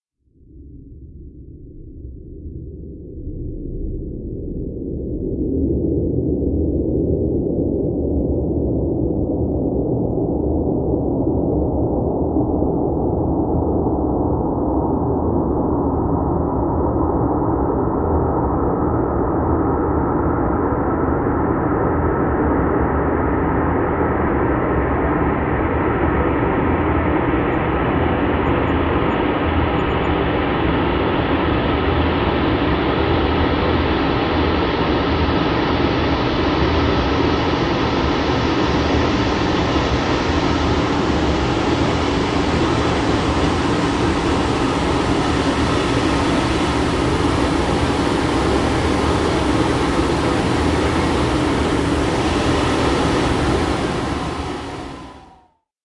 木质除尘机声音反转，带有动态均衡器中低频滤波器（慢通）。
一条与此类似的轨道是我使用的作品之一。 反转录音后，我用Audition的DynamicEQ lowmidhigh (slowpass)filter制作了这个。